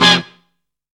TRUMPET SLAM.wav